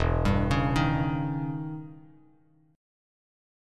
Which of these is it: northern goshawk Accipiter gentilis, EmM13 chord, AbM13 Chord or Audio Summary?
EmM13 chord